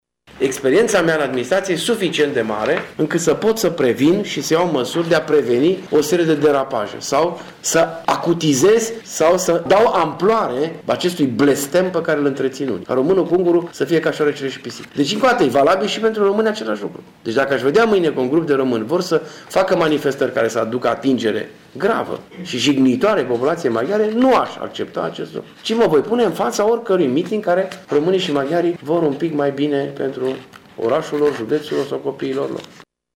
Primarul municipiului a declarat astăzi, într-o conferinţă de presă, că oraşul nu trebuie să fie „poligonul de încercare al mediocrităţilor”.